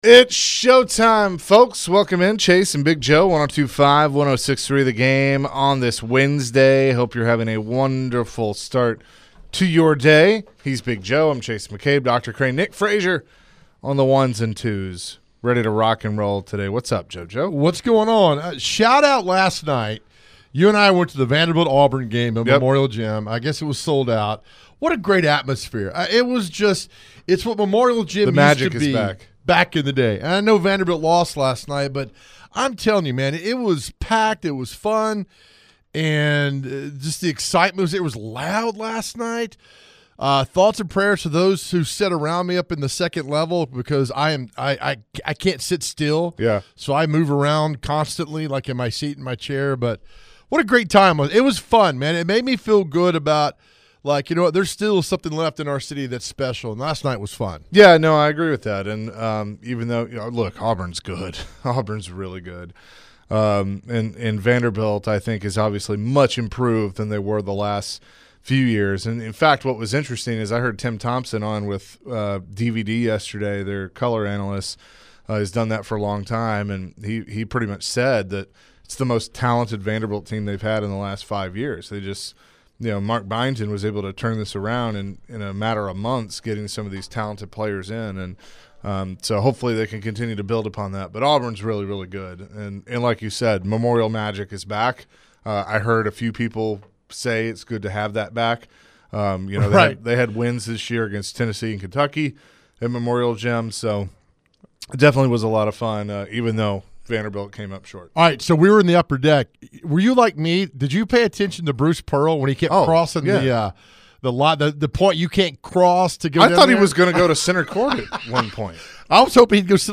The guys got to some phone calls about their popular question of the day.